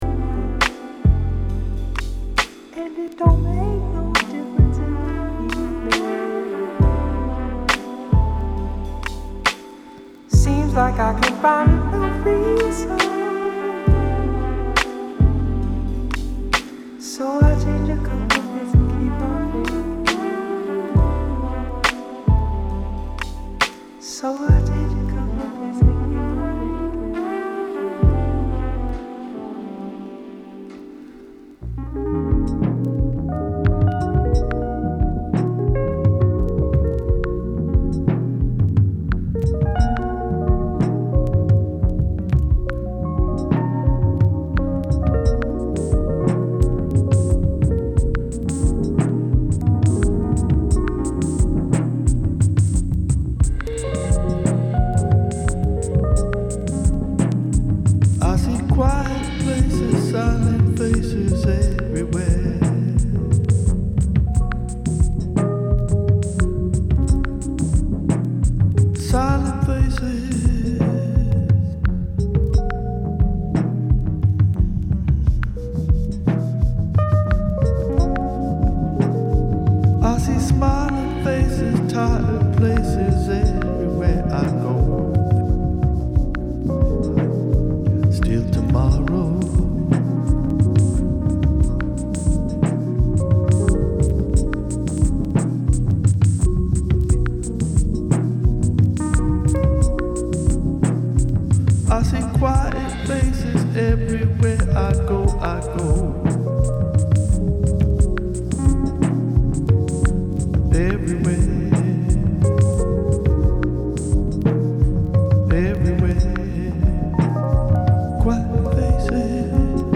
ダイジェストになります。